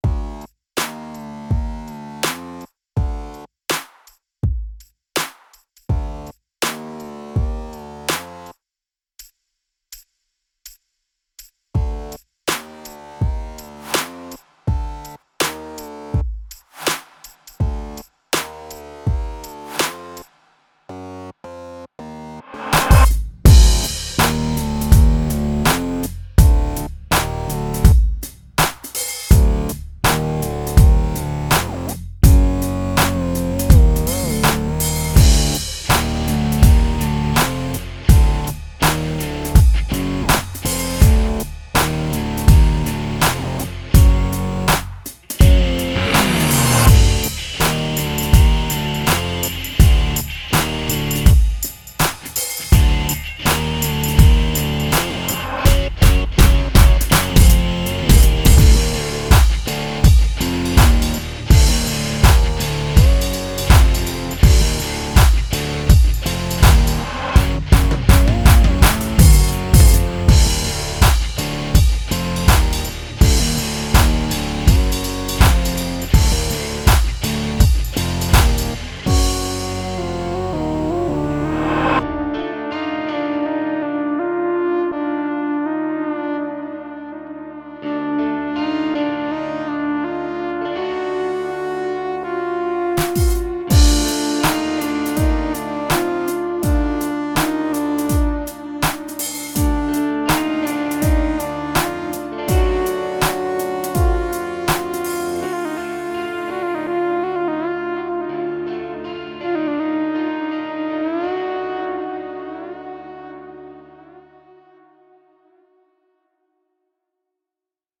tema dizi müziği, heyecan aksiyon enerjik fon müziği.